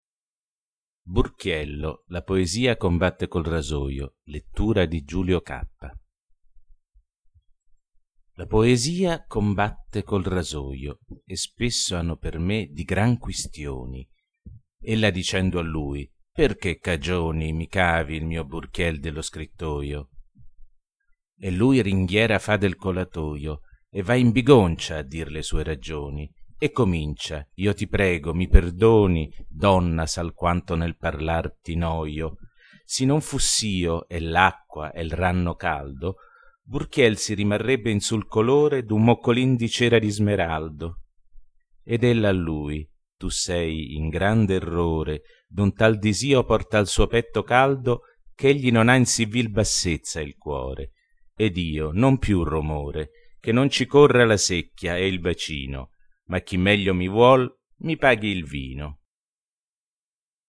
Poesie recitate da artisti